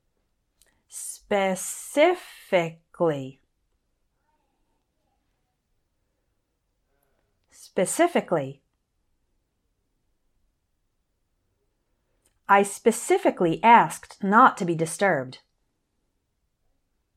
I’ll say each one once slowly, once at normal speed, and then I’ll say an example sentence – so listen and repeat after me each time.